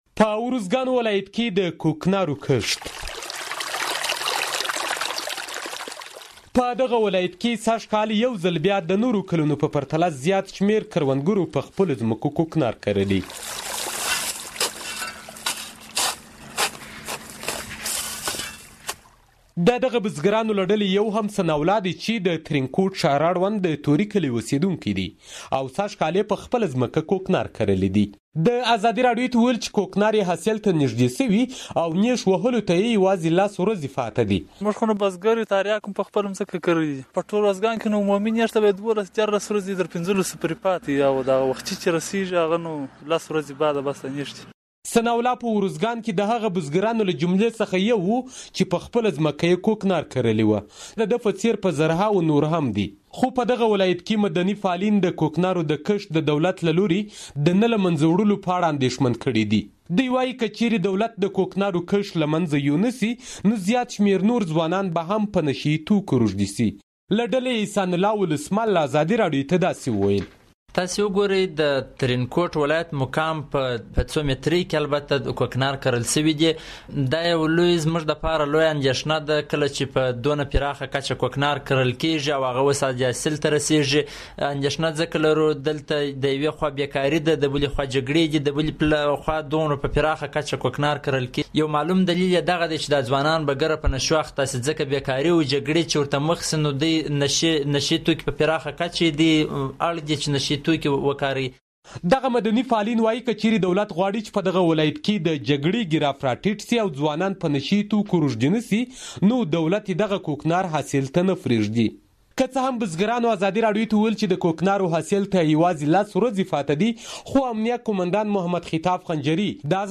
فیچر راپور